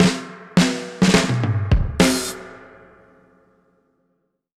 Index of /musicradar/dub-drums-samples/105bpm
Db_DrumsA_Wet_105_04.wav